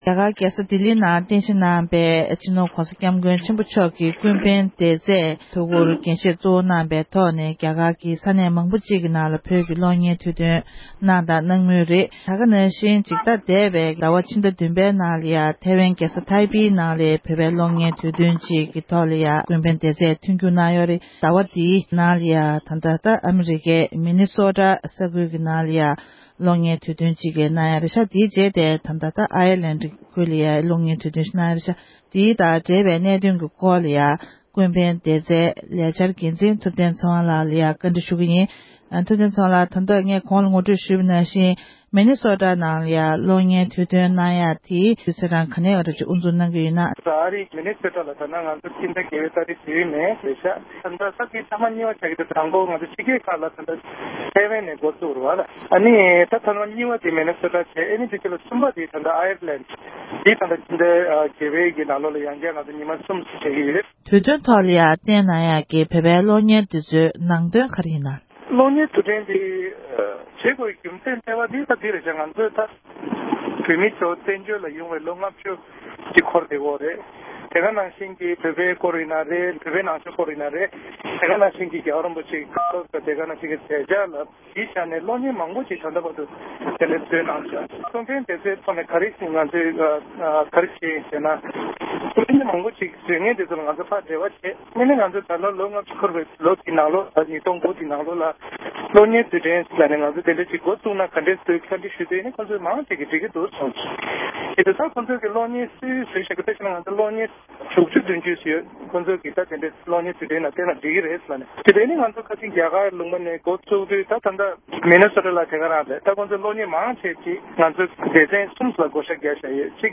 གནས་འདྲི